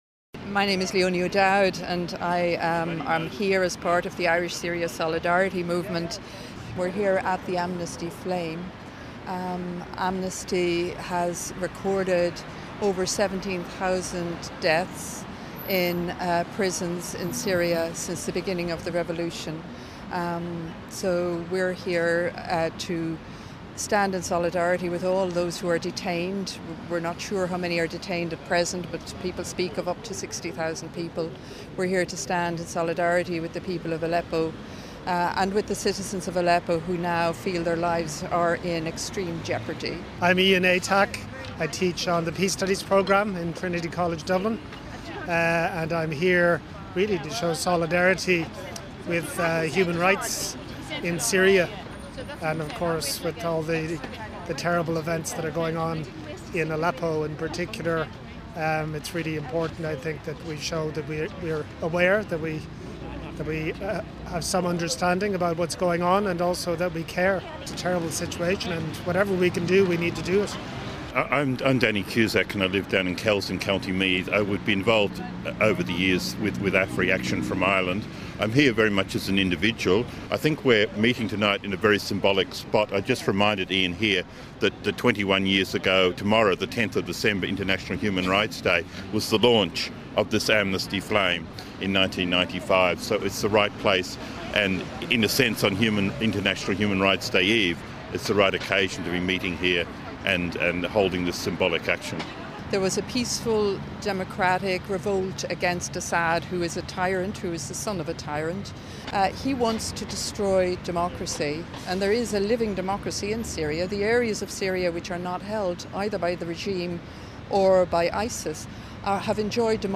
Last night in Dublin as thousands of pre Christmas shoppers headed for home the Irish Syria Solidarity Movement held an event at the "Amnesty Flame" on Memorial Road to remember the thousands of Syrians arrested and tortured by the Assad regime and other groups.